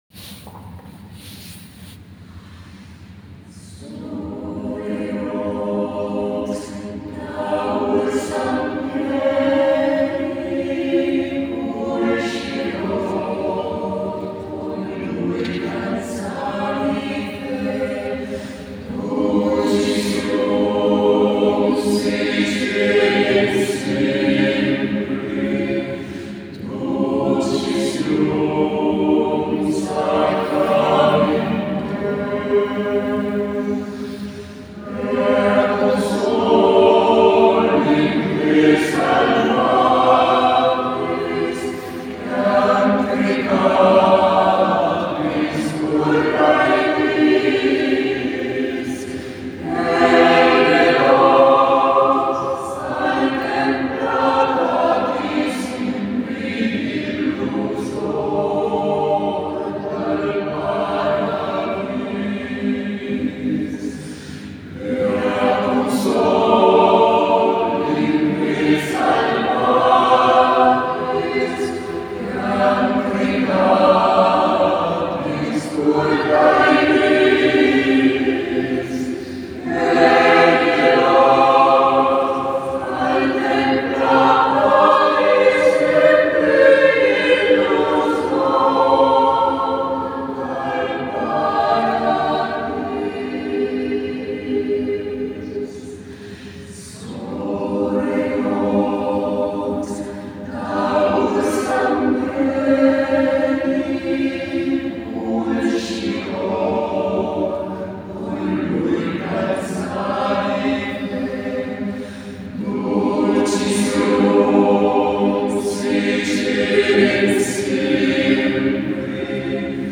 Concerto del 19 novembre 2022 – Chiesa di San Bartolomeo a Treviso